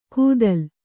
♪ kūdal